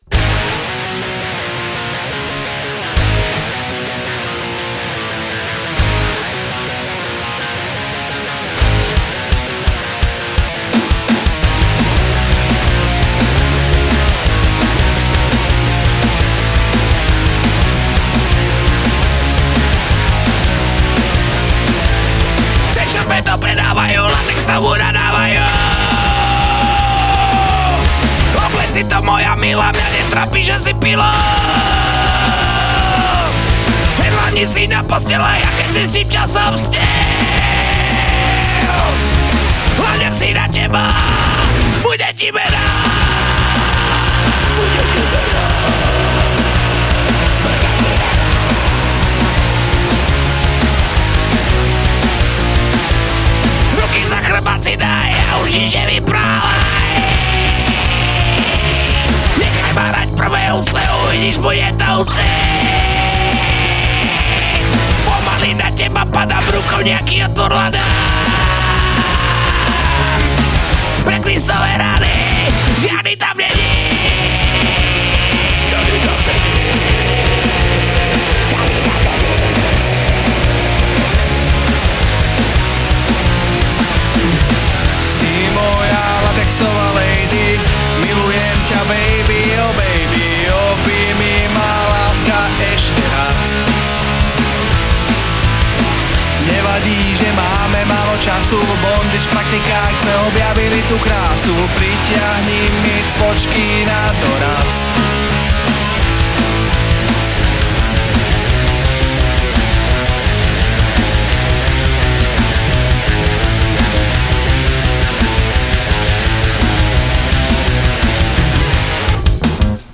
Žánr: Rock
gitara, spev
basgitara